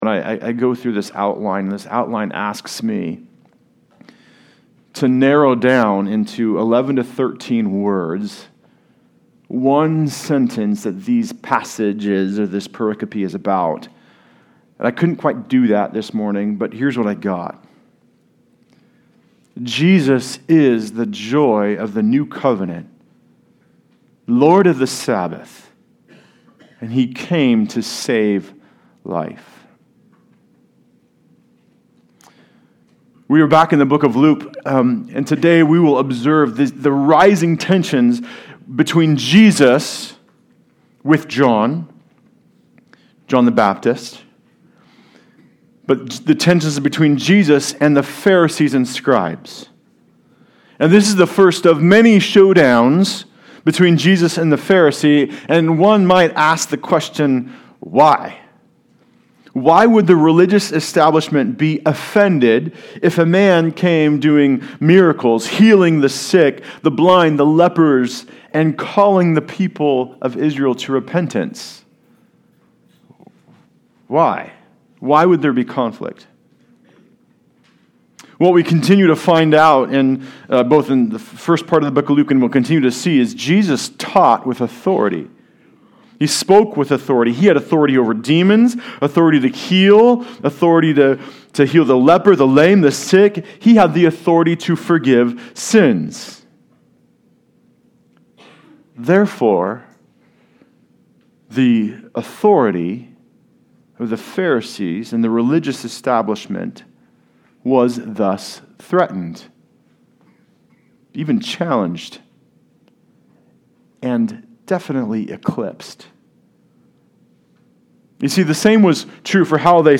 Passage: Luke 5:33 - 6:11 Service Type: Sunday Service